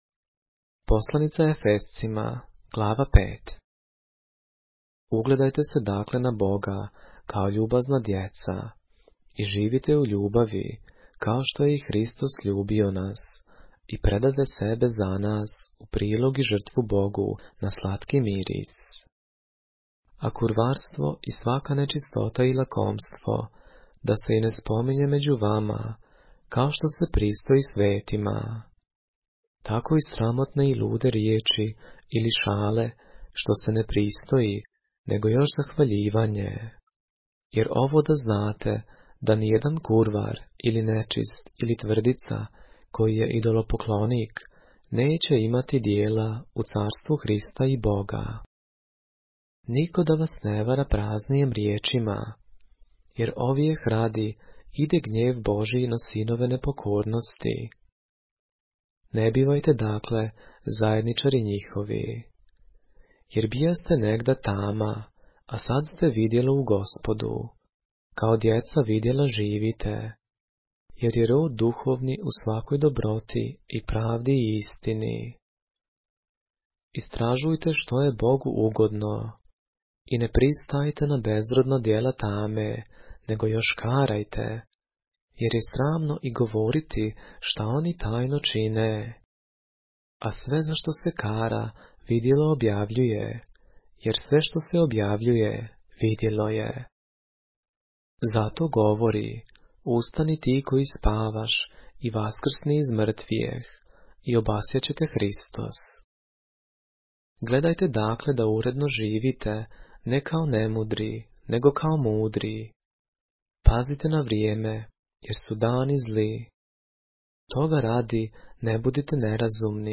поглавље српске Библије - са аудио нарације - Ephesians, chapter 5 of the Holy Bible in the Serbian language